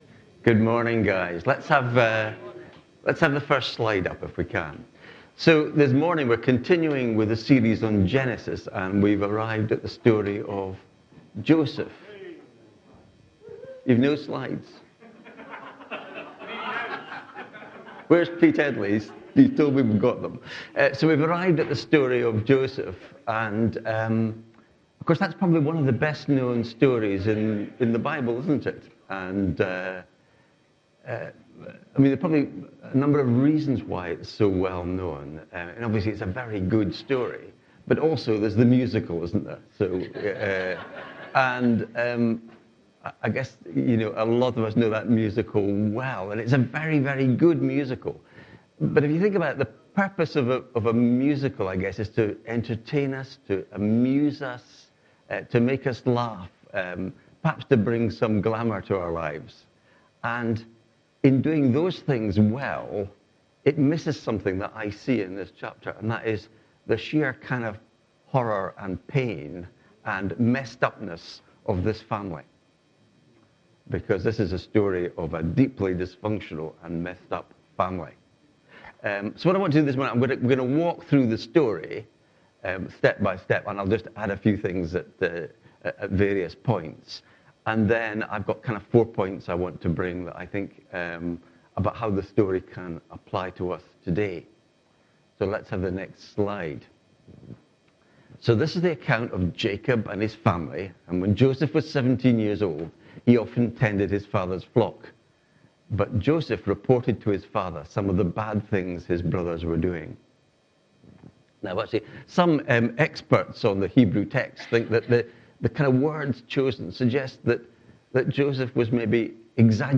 This sermon explores the biblical story of Joseph from Genesis 37, focusing on themes of family dysfunction, God’s sovereignty, and the redemptive power of God even in the midst of suffering.